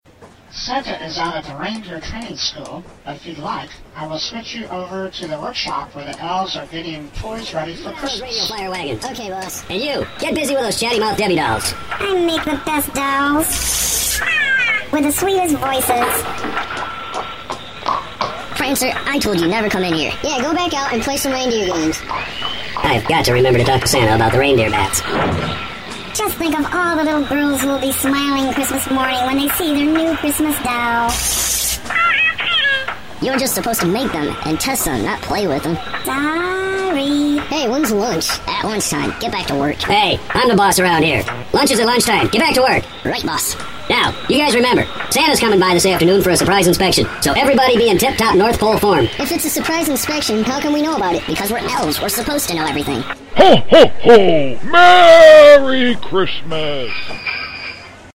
• Listen to the elves work